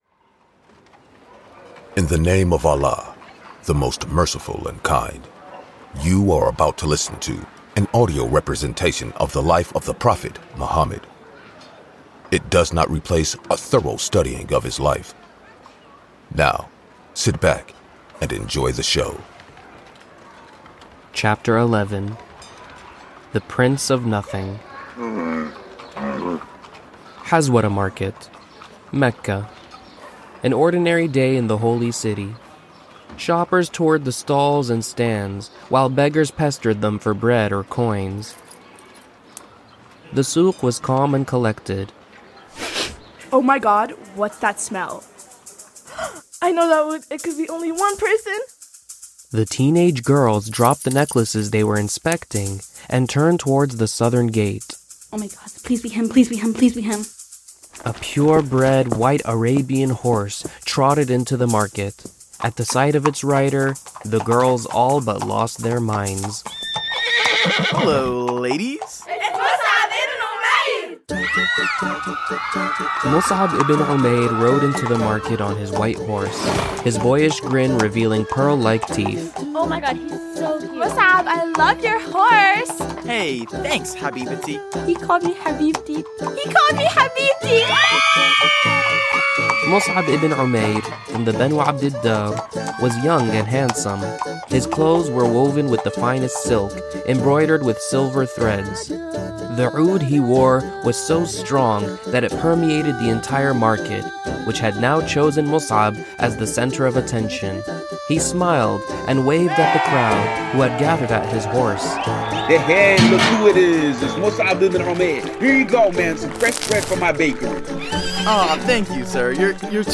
This audio adventure is complete with sound effects, actors, and ambiances to make the story of the Islamic prophet come to life.